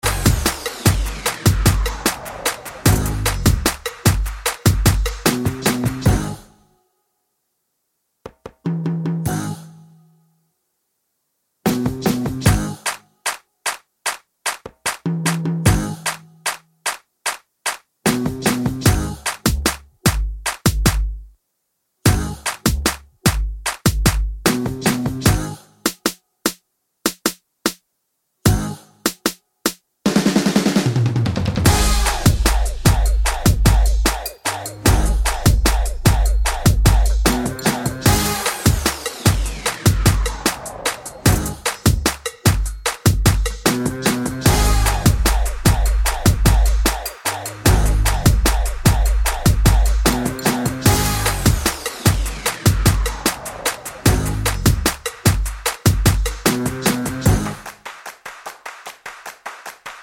Down 4 Semitones R'n'B / Hip Hop 3:20 Buy £1.50